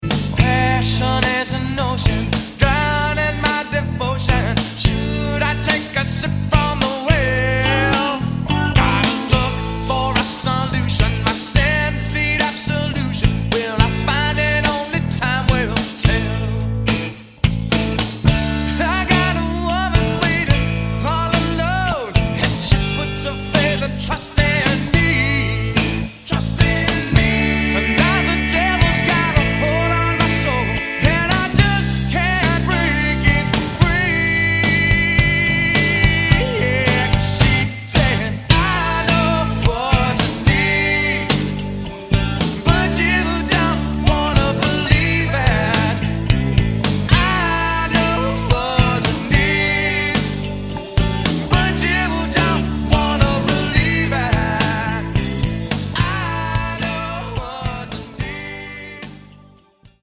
Lead vocals, keyboards.
Guitars, vocals, harmonica.
Bass, vocals.
Drums, percussion.